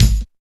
137 KICK.wav